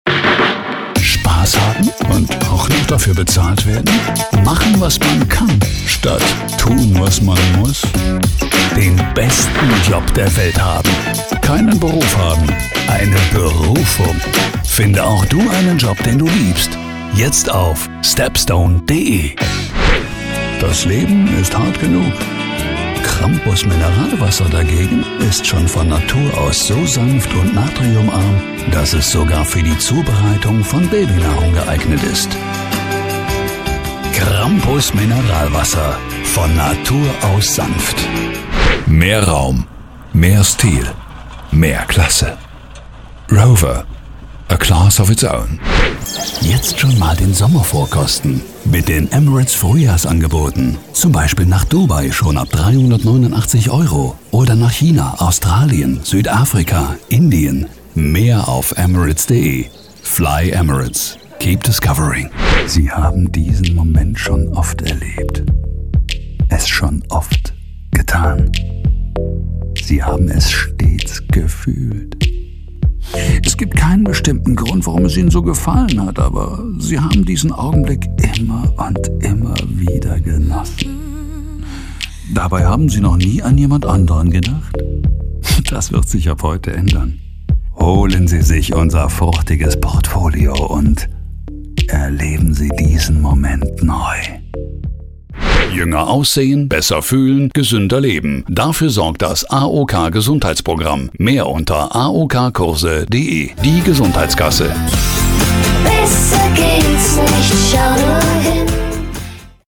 Bekannte, dynamische, kräftige und markante Stimme; mit hohem Wiedererkennungswert in voller warmer Stimmlage.
Professioneller deutscher Sprecher (seit 1994) mit eigenem Studio.
Sprechprobe: Werbung (Muttersprache):
Werbung--Collage-2_Projekt.mp3